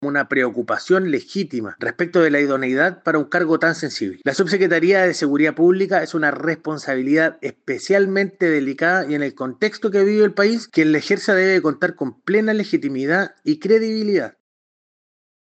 En medio de ese debate, el vicepresidente del PNL y diputado electo, Hans Marowski, salió a precisar que las declaraciones de Kaiser no deben entenderse como una presión, sino como una señal de preocupación institucional.